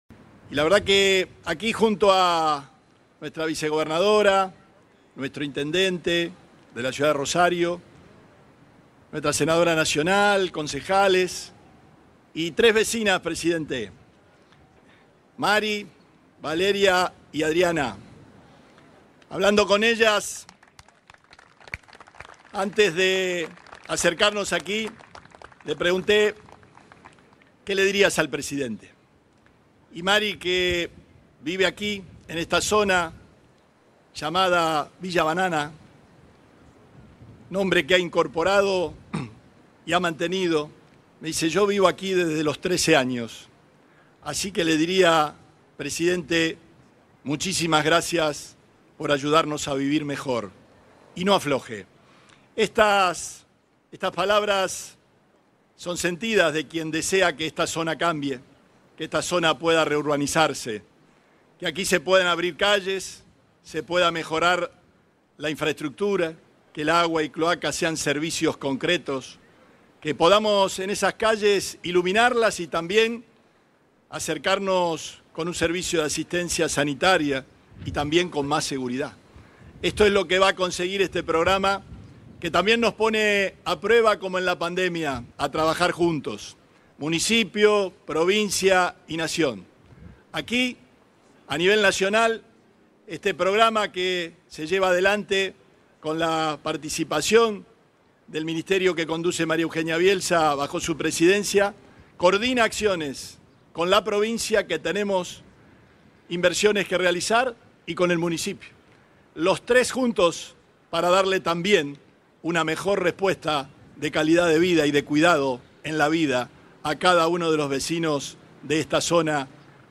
Este viernes, el gobernador Omar Perotti, conectado por videoconferencia con el presidente Alberto Fernández, hizo entrega de viviendas que integran un complejo urbanístico en Granadero Baigorria, desarrollado por el Ministerio Nacional de Desarrollo Territorial y Hábitat.